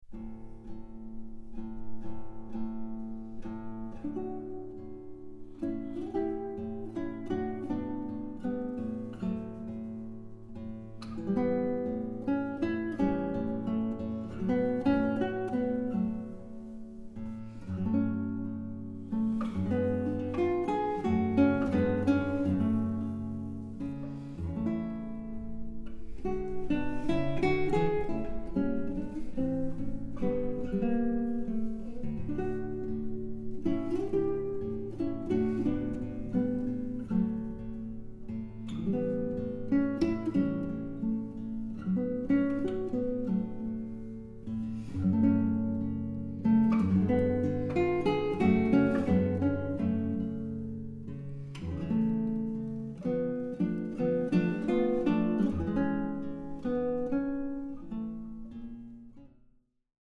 Guitar
richly colored and precisely articulated guitar sound